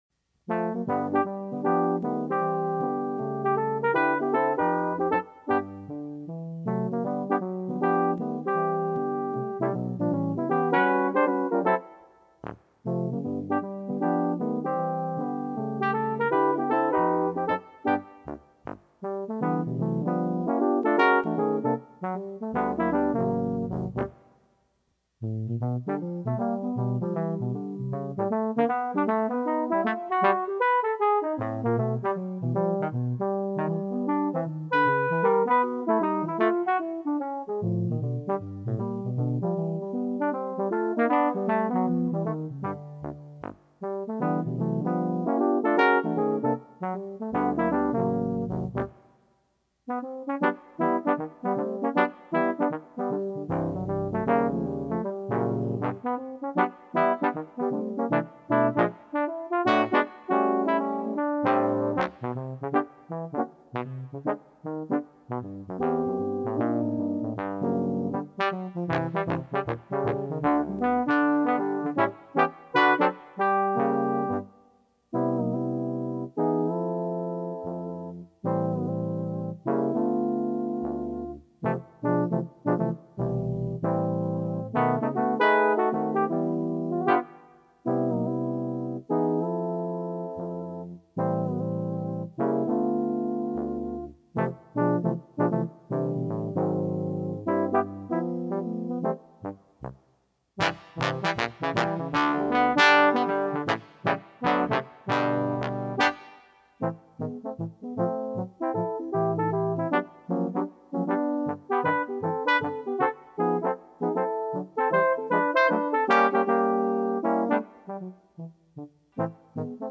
in a jazz style!
jazz